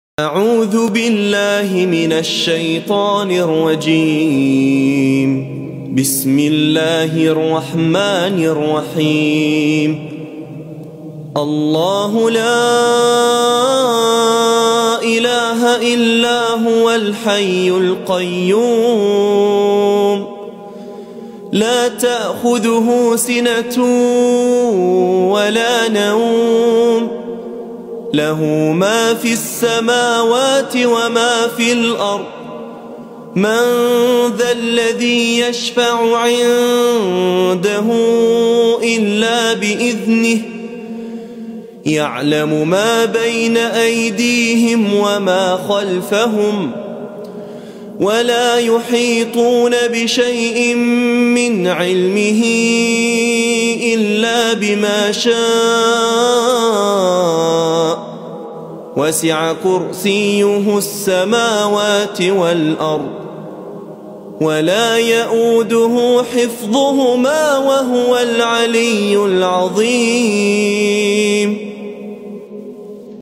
Ayatul Kursi — Full Beautiful Recitation
Ayatul-Kursi-Full-Beautiful-Recitation.mp3